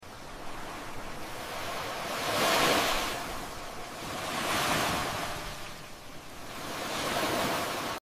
Satisfying ASMR videos of Waves sound effects free download
Satisfying ASMR videos of Waves of Dollar's 💵